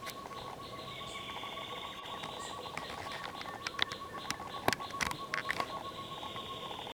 Tovaca-campainha (Chamaeza campanisona)
Nome em Inglês: Short-tailed Antthrush
Fase da vida: Adulto
Localidade ou área protegida: Parque Nacional Iguazú
Condição: Selvagem
Certeza: Gravado Vocal